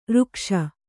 ♪ rukṣa